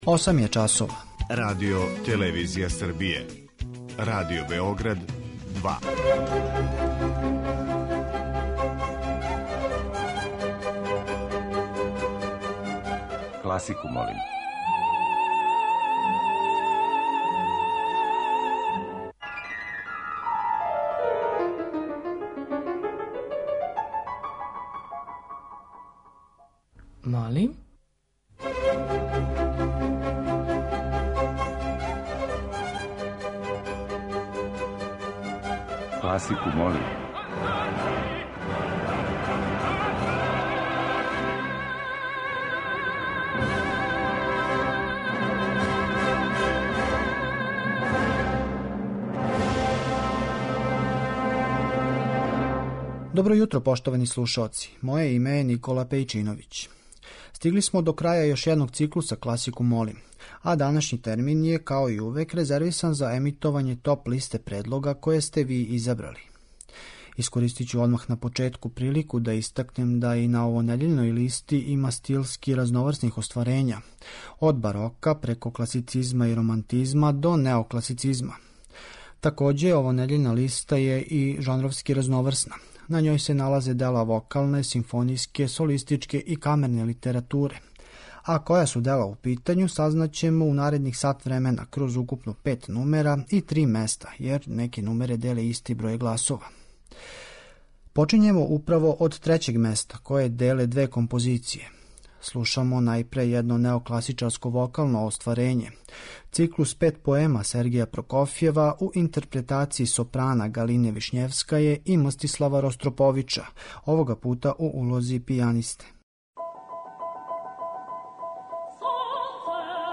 Недељна топ-листа класичне музике Радио Београда 2